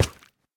assets / minecraft / sounds / step / coral5.ogg
coral5.ogg